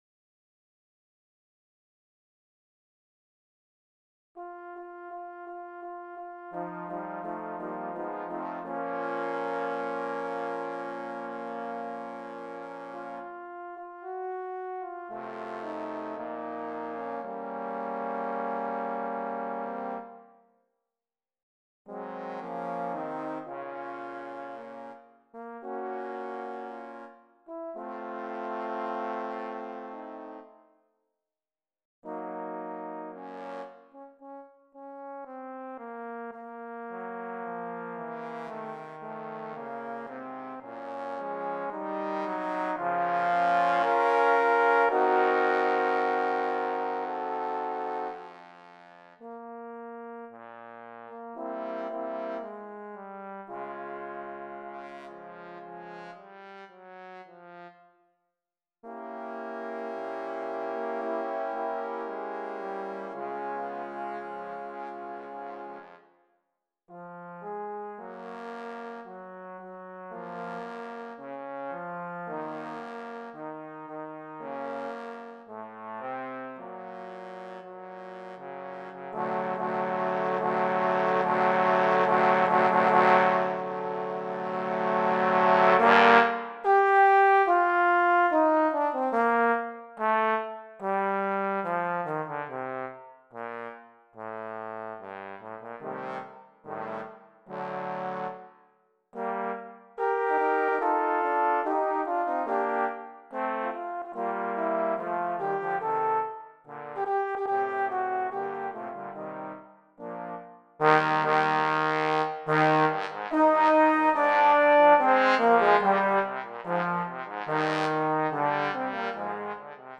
Trombone Octet Edition
1 alto + 1 alto/tenor + 4 tenor + 2 bass
More power with 8 trombones!